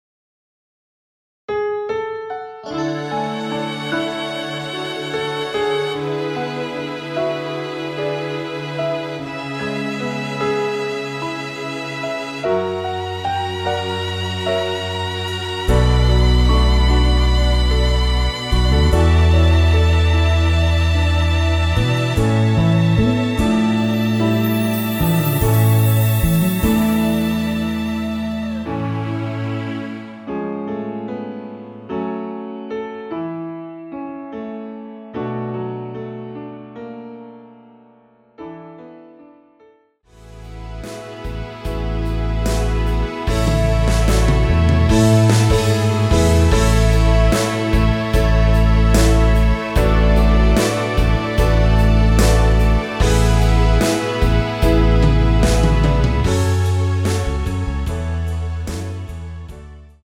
원키에서(+2)올린 MR입니다.
앞부분30초, 뒷부분30초씩 편집해서 올려 드리고 있습니다.